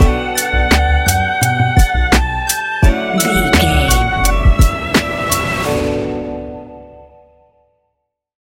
Ionian/Major
D♭
laid back
Lounge
sparse
new age
chilled electronica
ambient
atmospheric